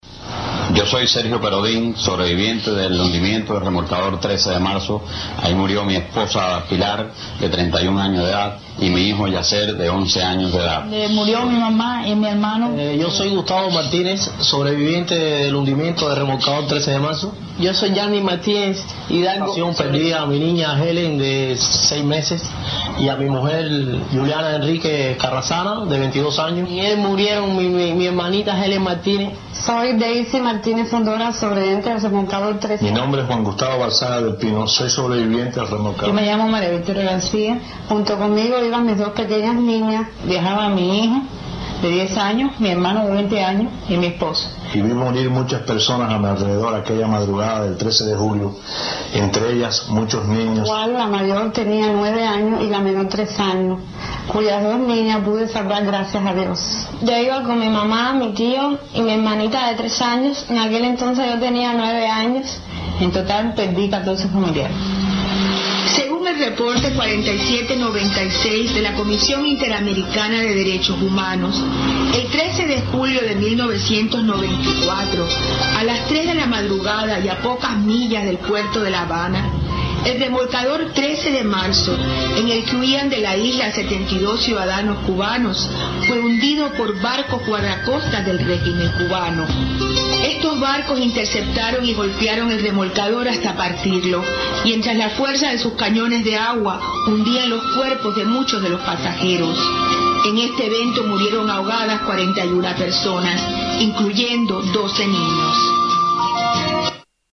Hablan los sobrevivientes del remolcador 13 de marzo